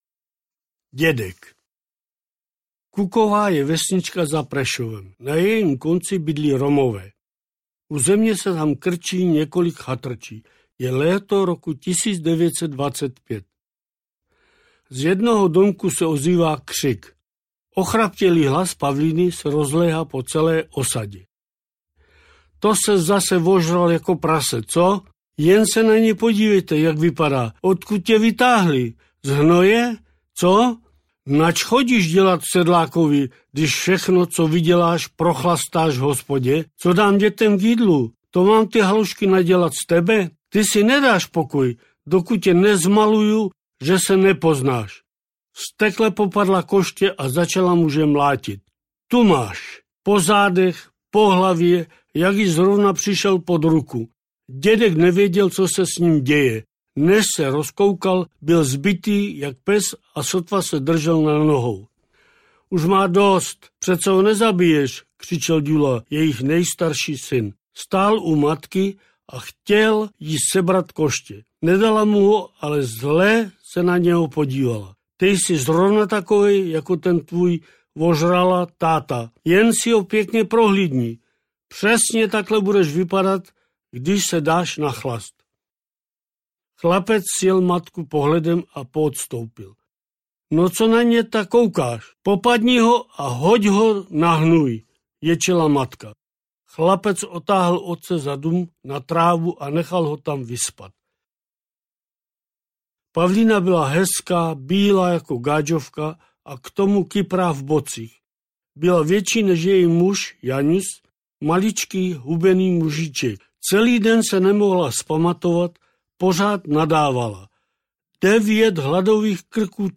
Ukázka z knihy
Celý popis Rok vydání 2019 Audio kniha Ukázka z knihy 169 Kč Koupit Ihned k poslechu – MP3 ke stažení Potřebujete pomoct s výběrem?